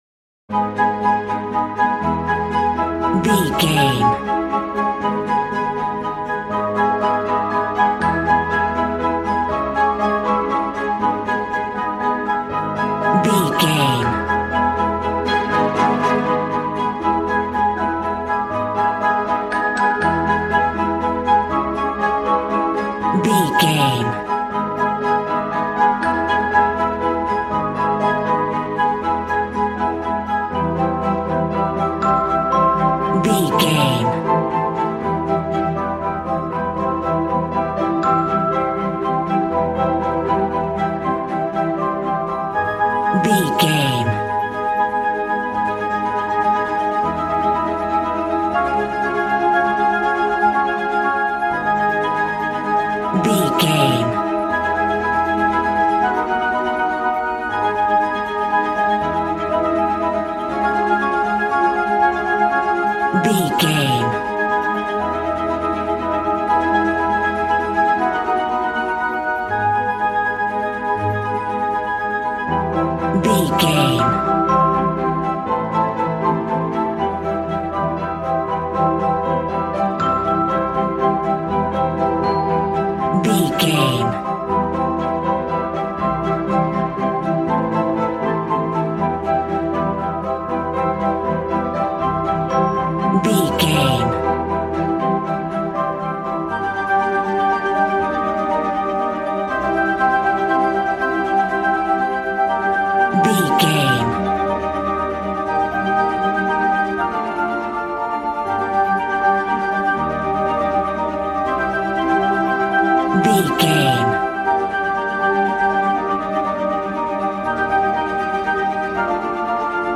Regal and romantic, a classy piece of classical music.
Aeolian/Minor
A♭
regal
strings
violin